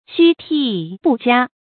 吁咈都俞 yù fú dōu yú
吁咈都俞发音